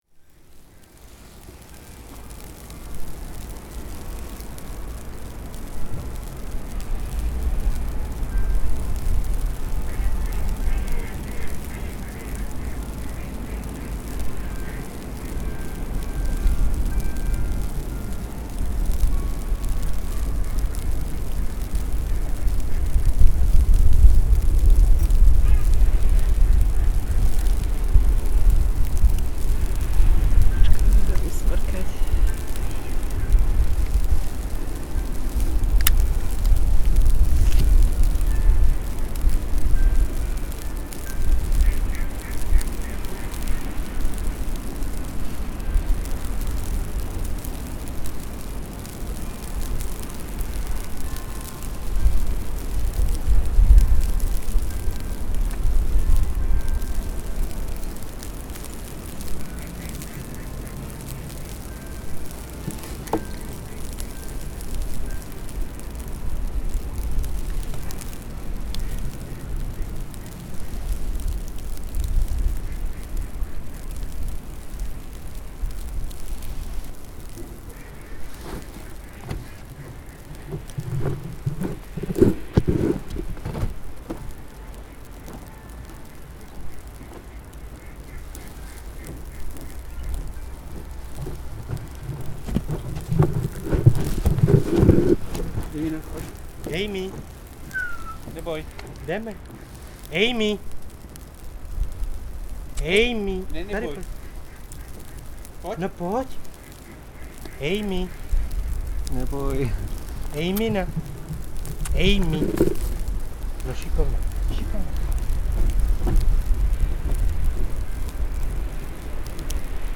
Vločky a pejskaři na železničním mostě
Snowflakes and people walking dogs in railway's bridge
Tags: exterior people nature animals snow dogs bridges
Sparkling snowflakes.
You can hear the bells from Vyšehrad, waterfowl and dogs. People walking dogs are rushing.